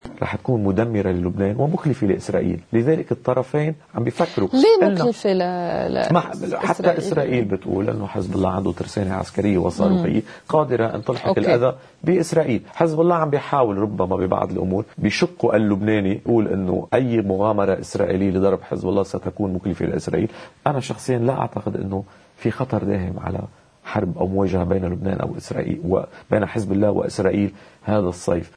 مقتطف من حديث المحلل السياسي